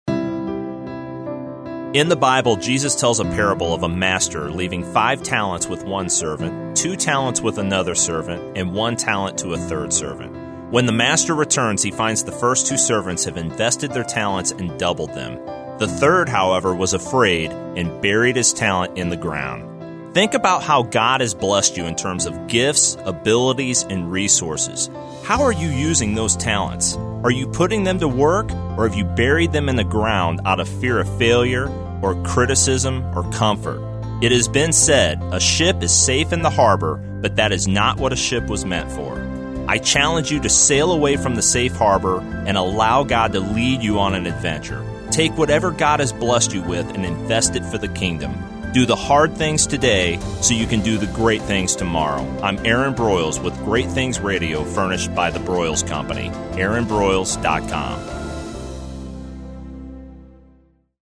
I’m excited to introduce Great Things Radio (1 min motivational messages) airing now on Bott Radio Network on 91.5 FM in St. Louis at approximately 5:35 p.m. CST (top of the second break in the Bible Answer Man Hank Hanegraaff broadcast).